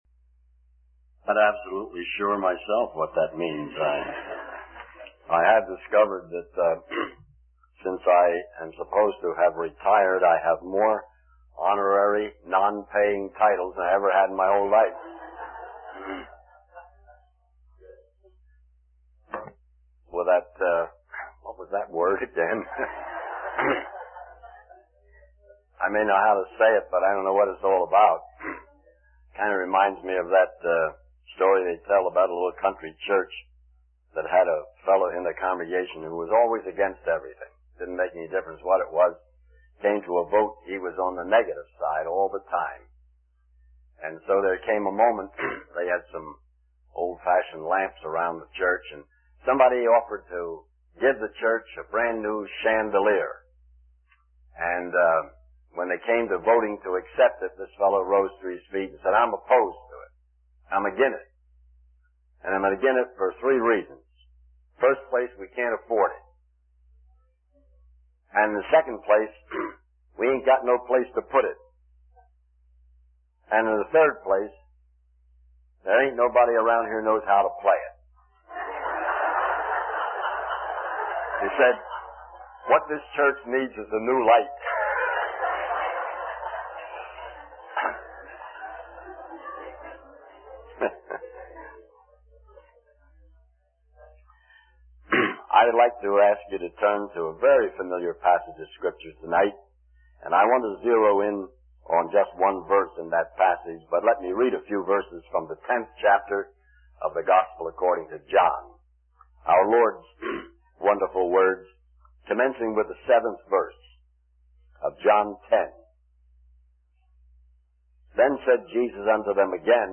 In this sermon, the speaker addresses the fear-filled culture we live in today. He highlights the various sources of fear, including personal struggles, loneliness, and the general sense of hopelessness and insecurity. The speaker also mentions President Carter's speech at the UN Assembly, where he acknowledges the failure to achieve lasting peace.